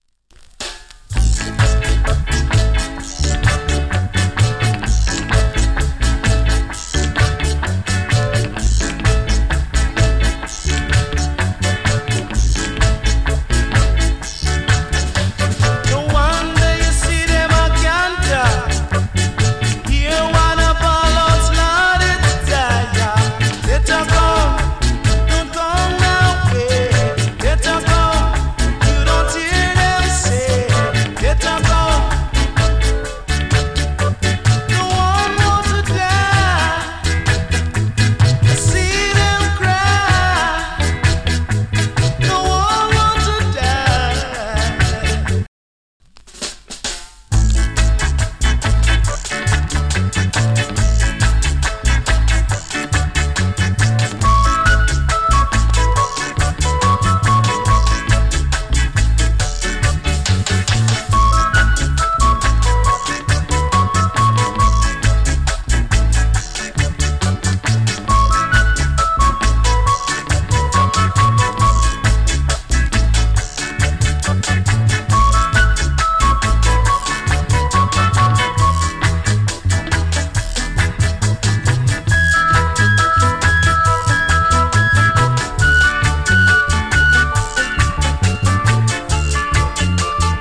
NICE VOCAL EARLY REGGAE !! FLIP SIDE は GREAT ORGAN INST !!